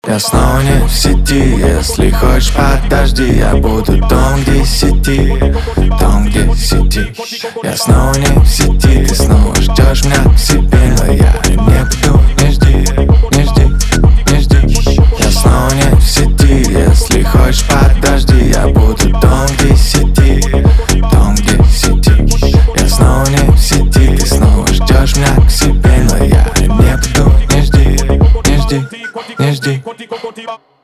русский рэп , битовые , басы , качающие